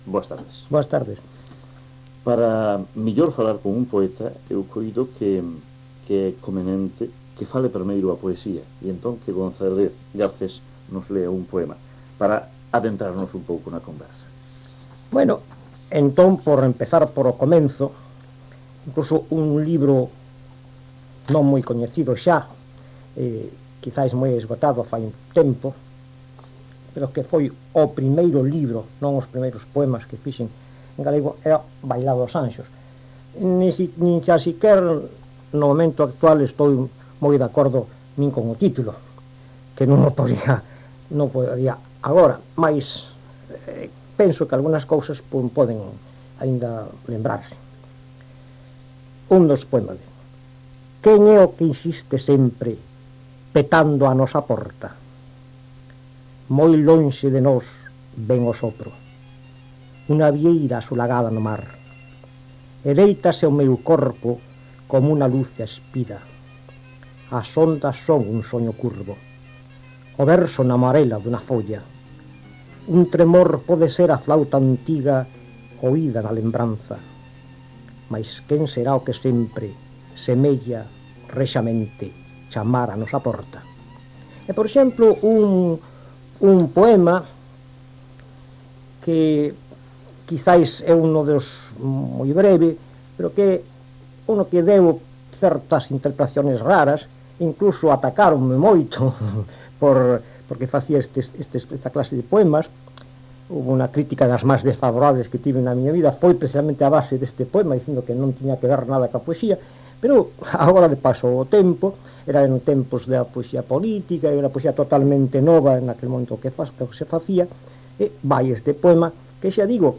Entrevista a Miguel González Garcés – Poetas na súa voz – Colectivo Egeria